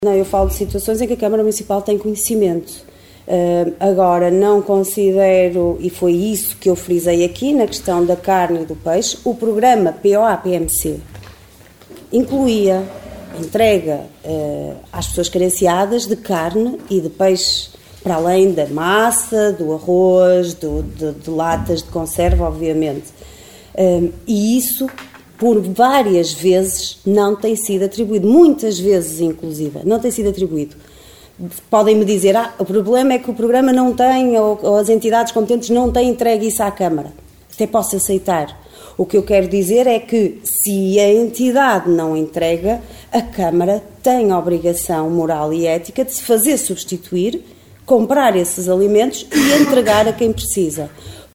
O alerta foi deixado pela vereadora da Coligação O Concelho em Primeiro, Liliana Silva, no período antes da ordem do dia da reunião de Câmara realizada no passado dia 21 de fevereiro.
Liliana Silva a dar conta de situações de carência preocupantes no concelho de Caminha.